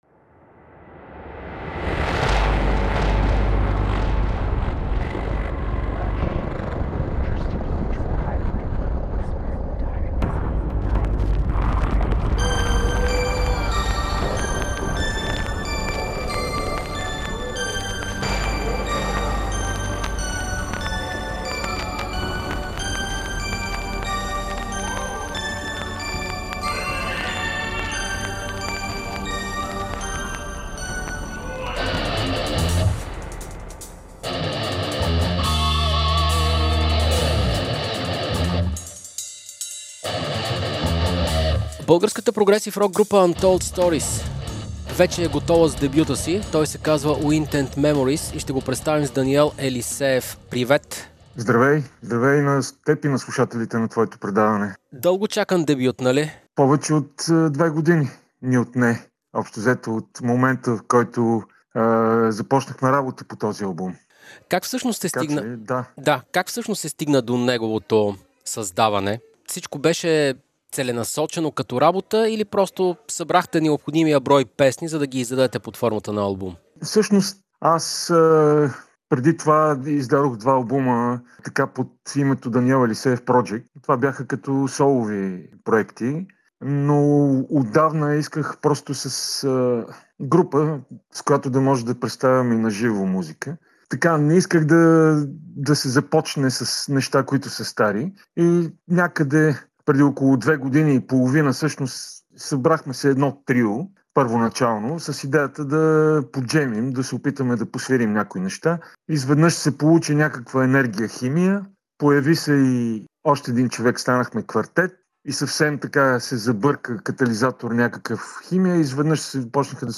В разговора